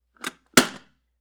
Part_Assembly_13.wav